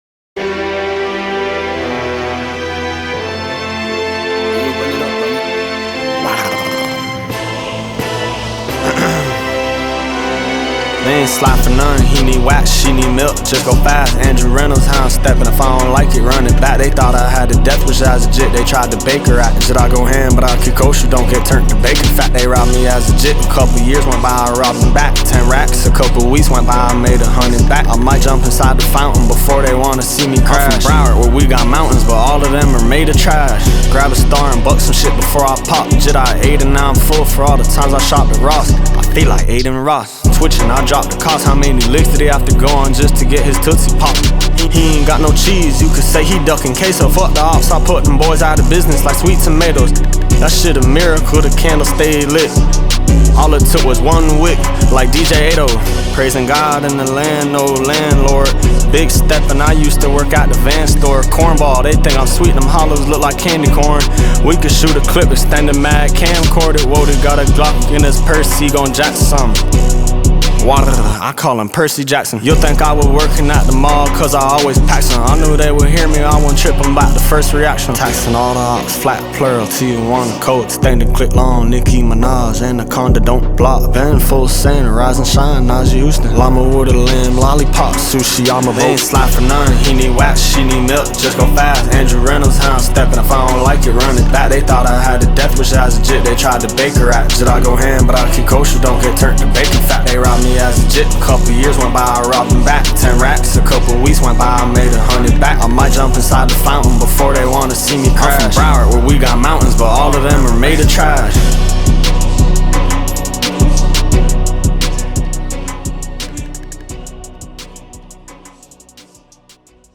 Hip Hop
a sound that was both robust and innovative